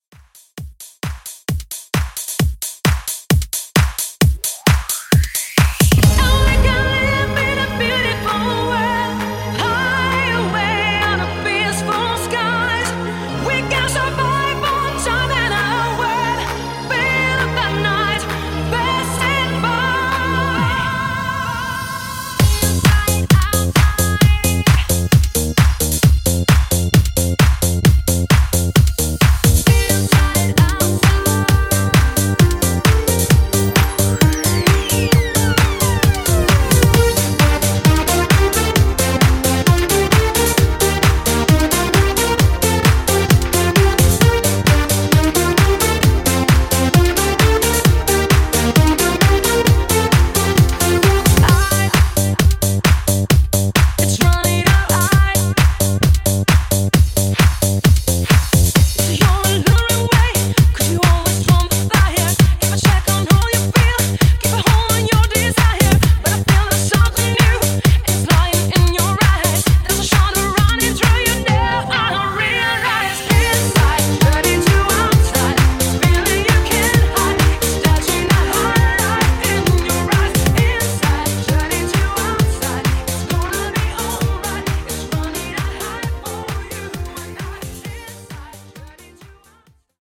Dance Redrum)Date Added